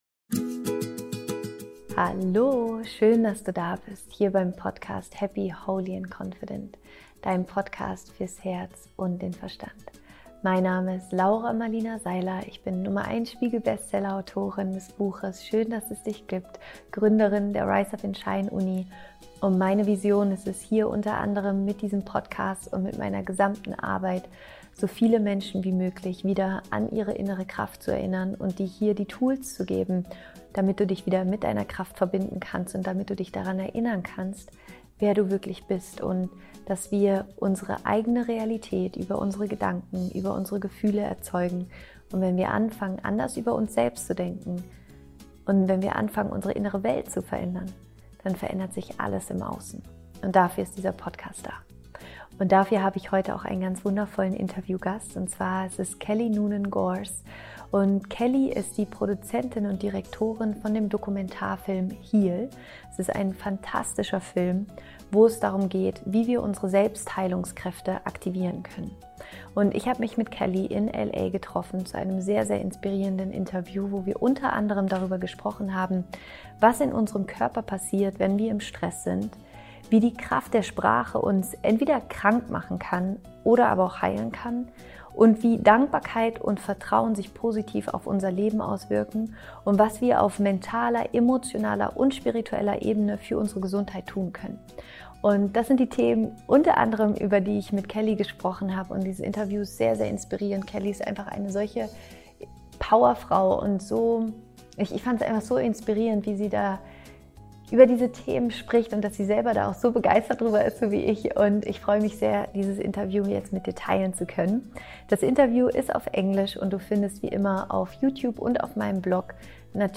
** Das Interview ist auf Englisch.